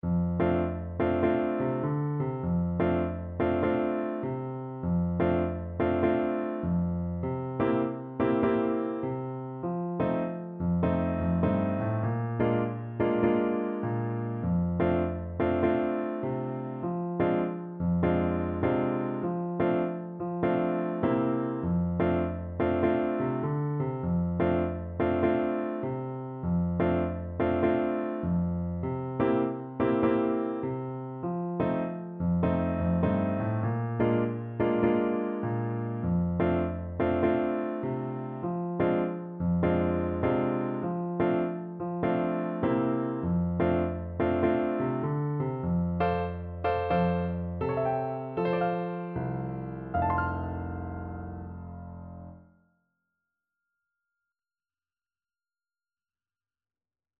Traditional Music of unknown author.
Moderate swing